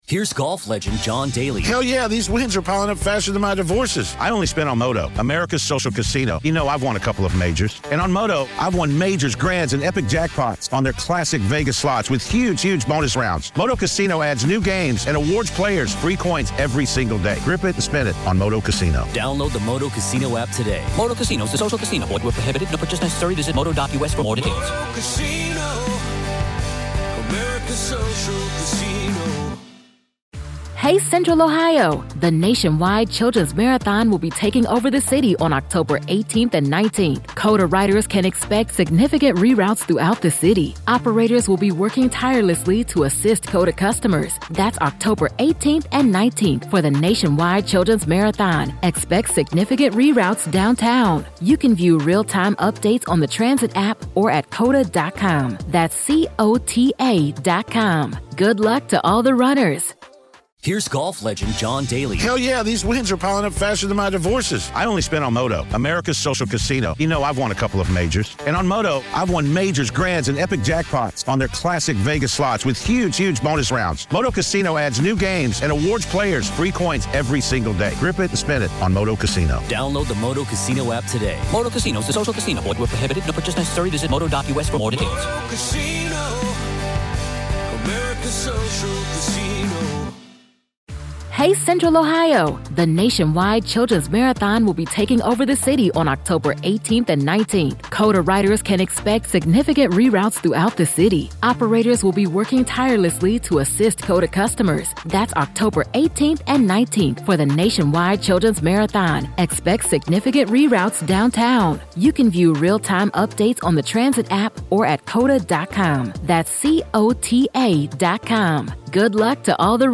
True Crime News & Commentary